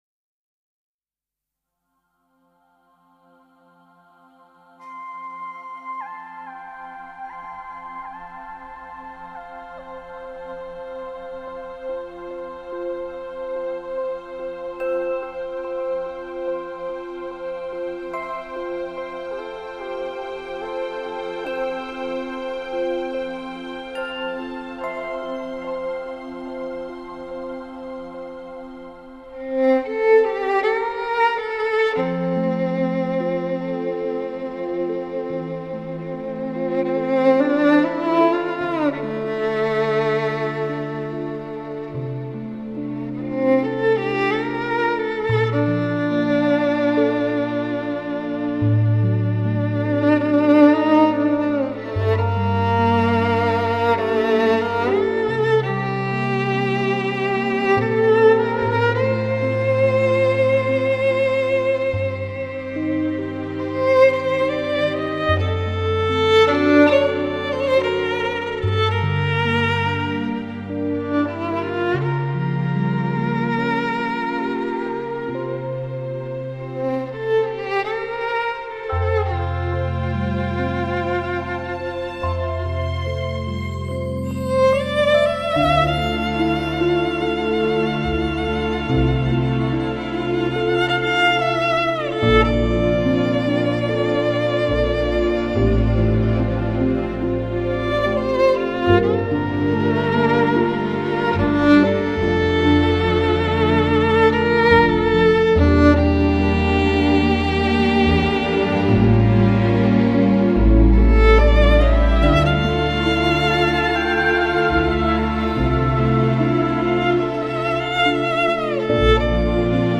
延续着他们宁静、质朴而优雅的格调，充满着古典主义的美感和凯尔特音乐独有的欢快和乐观
凝重而厚实的音色中充满着对曲目内涵深厚的理解